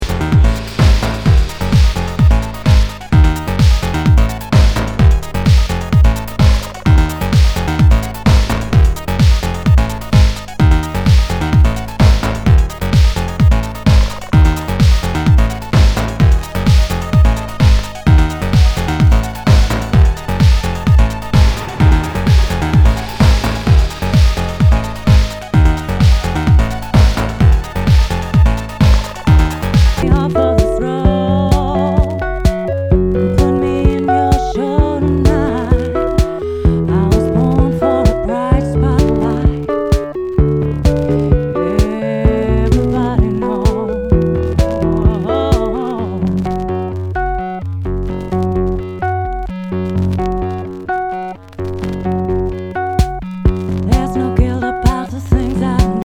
HOUSE/TECHNO/ELECTRO
ナイス！エレクトロ・テック・ハウス！
ジャケにスレキズ、抜けあり。全体に大きくチリノイズが入ります